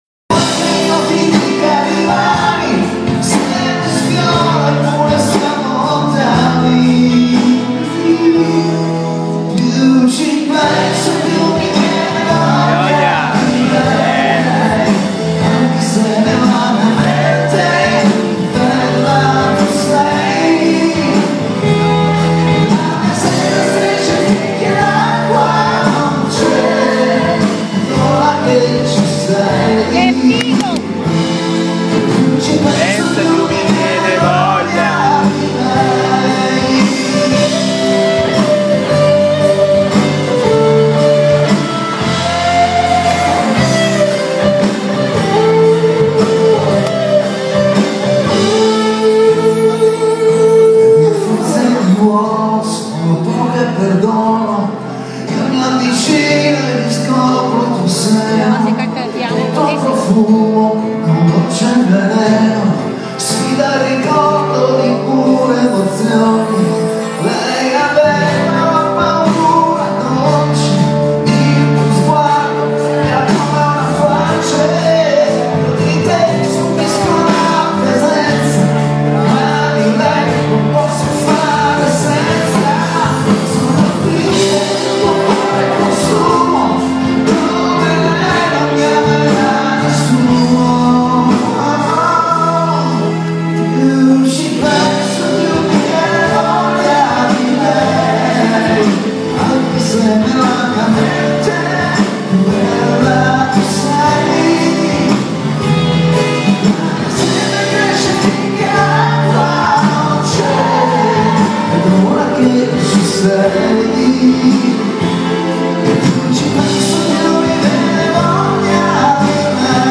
teatro del verme